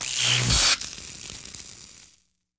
shock.ogg